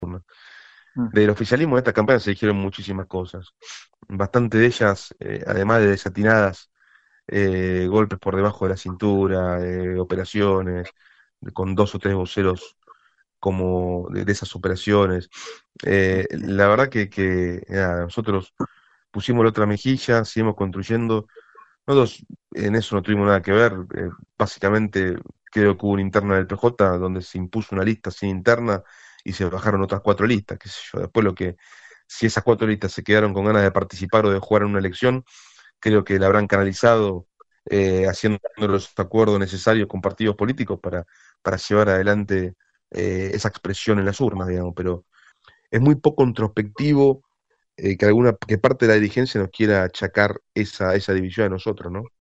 “El mayor porcentaje de votantes en la provincia, tuvo que ver que el gobernador se haya puesto al frente de esta campaña, movilizando a la gente que vaya a votar”, aseguró el ministro de Gobierno, Manuel Troncoso a Radio RD 99.1